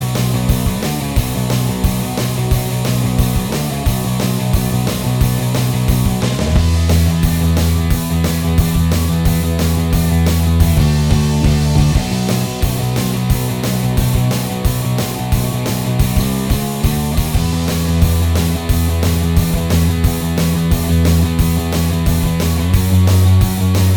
Minus Lead Guitar Rock 3:59 Buy £1.50